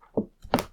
Book Open.ogg